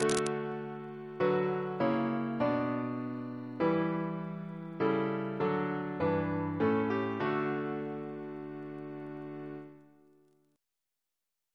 Single chant in D Composer: Thomas Attwood Walmisley (1814-1856), Professor of Music, Cambridge Reference psalters: ACB: 279